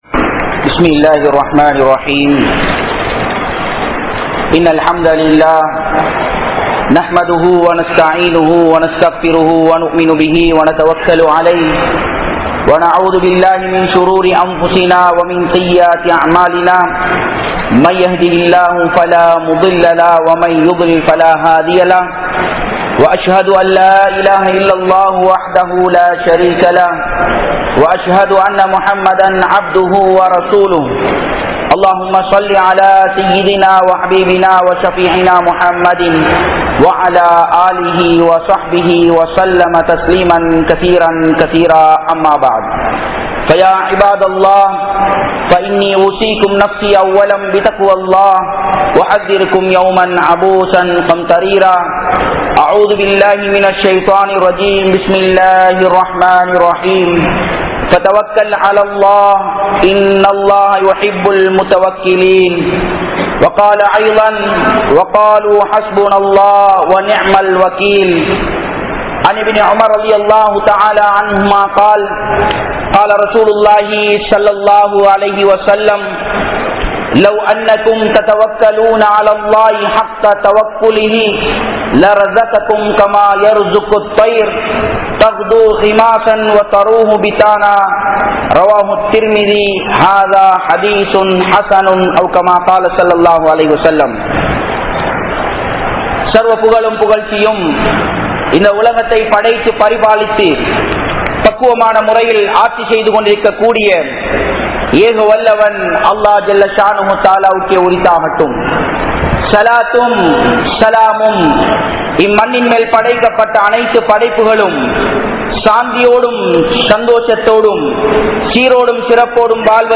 Allah`vai Nampungal (அல்லாஹ்வை நம்புங்கள்) | Audio Bayans | All Ceylon Muslim Youth Community | Addalaichenai